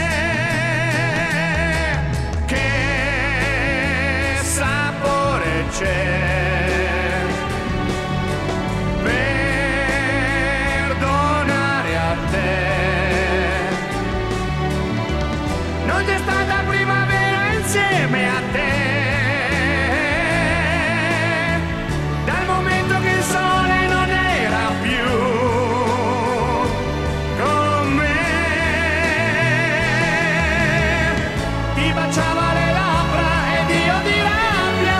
Жанр: Рок / Джаз / Классика